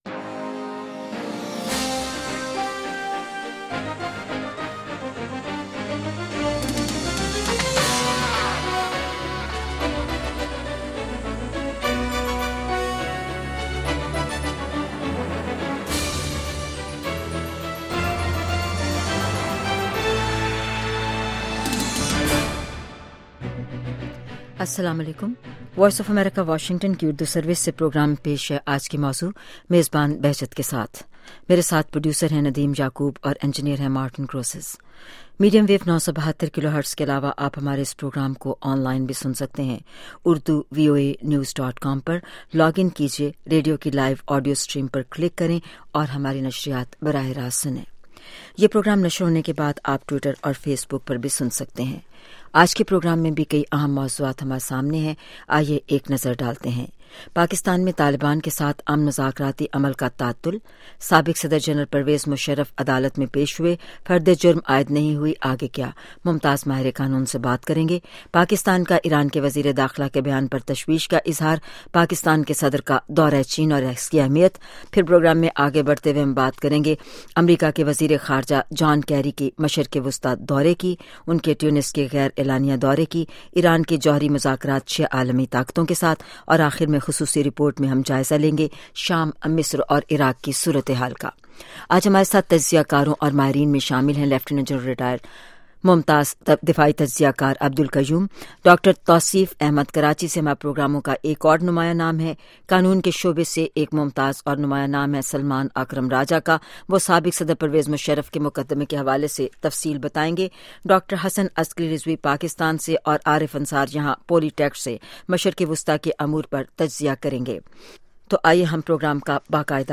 Sound Bites